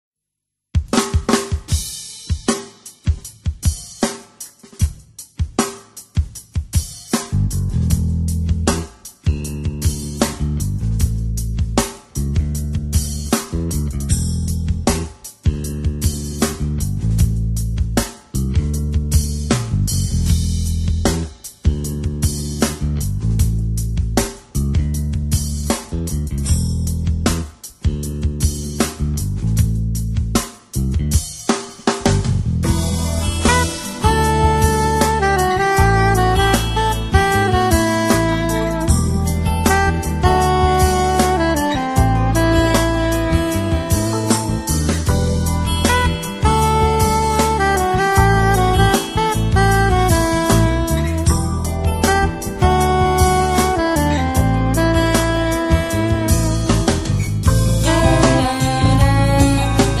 chitarre
Un collage di melodie fresche, di lontananze evocate.